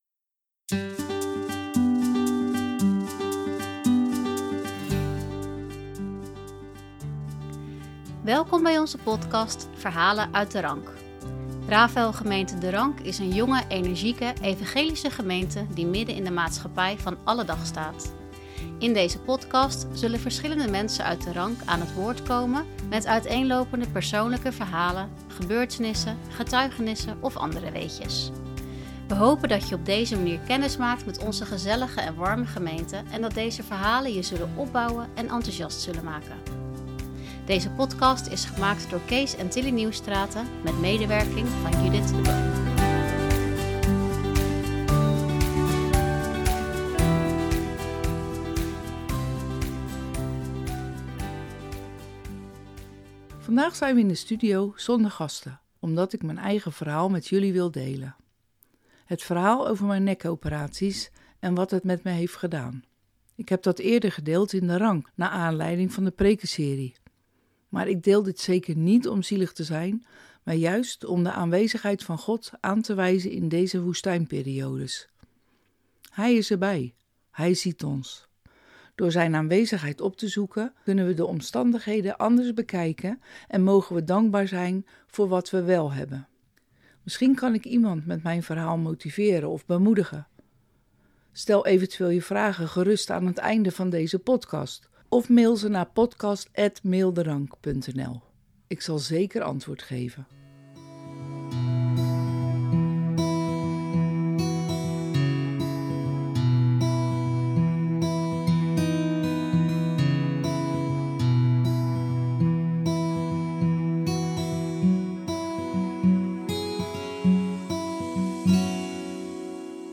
Opbouwende verhalen en getuigenissen van gemeenteleden uit Rafaël gemeente "De Rank" Rafaël gemeente 'de Rank' is een veelkleurige, jonge, energieke, evangelische gemeente die midden in de maatschappij van alle dag staat.